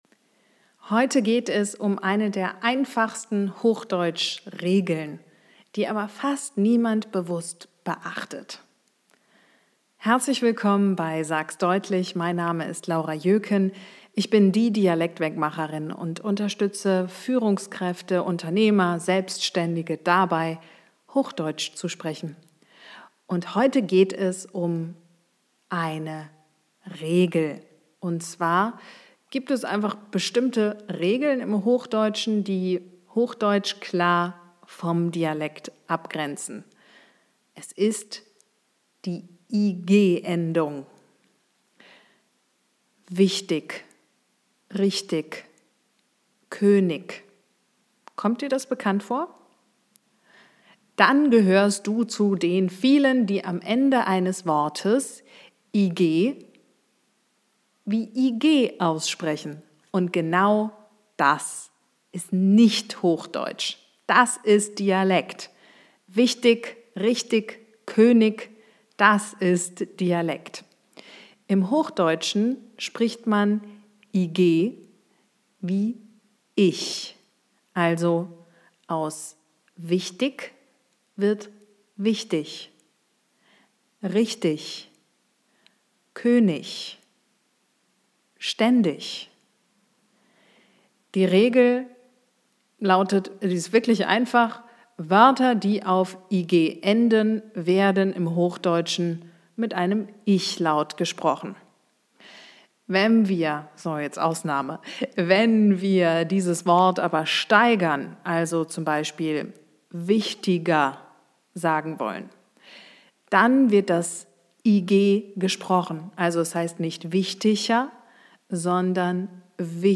– praktische Hörbeispiele
– eine Übung zum Mitsprechen